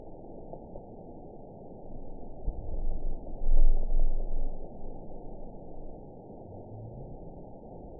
event 911248 date 02/19/22 time 01:12:11 GMT (3 years, 3 months ago) score 9.06 location TSS-AB01 detected by nrw target species NRW annotations +NRW Spectrogram: Frequency (kHz) vs. Time (s) audio not available .wav